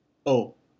“o” is pronounced “oh” like in “oh my gosh”
o-hiragana.mp3